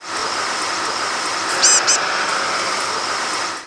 Blue-gray Gnatcatcher Polioptila caerulea
Flight call description A soft, mewing "bzew-bzew-bzew" (typically two to five notes).
Bird in flight.